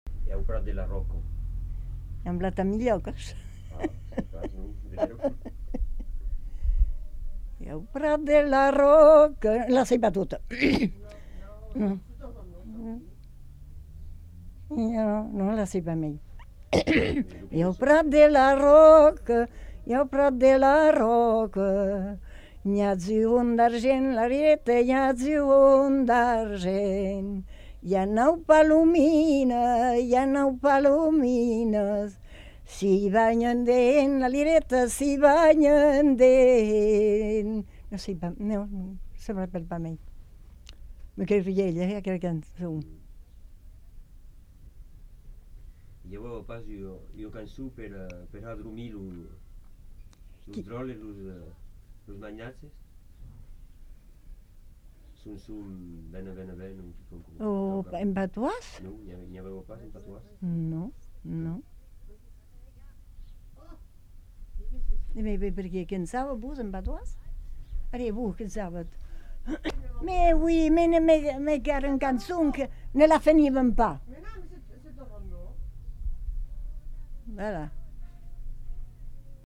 Lieu : Mont-de-Marsan
Genre : chant
Effectif : 1
Type de voix : voix de femme
Production du son : chanté
Danse : rondeau